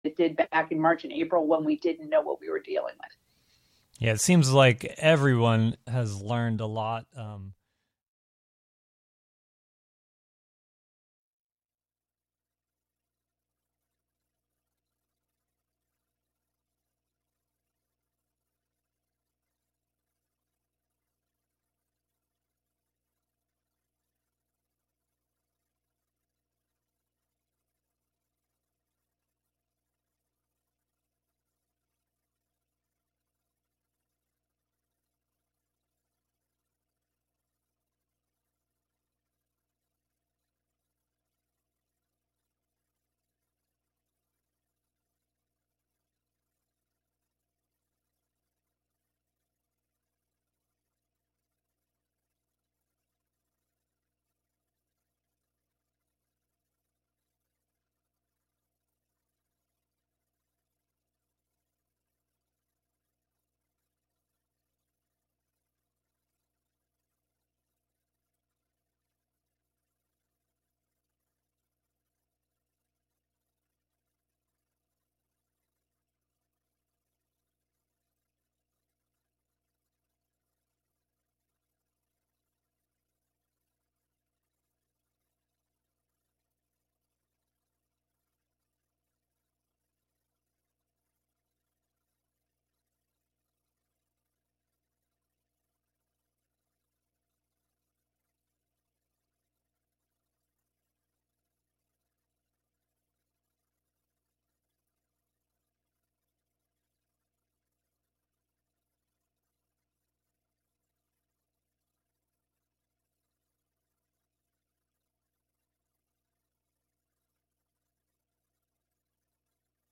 This broadcast marks the end of our seventh year on WGXC-FM and we are taking this opportunity to reflect on the best moments from 2020.
On each show, invited guests are asked to discuss a number of items that they would take with them to their Catskill Cabin get-away.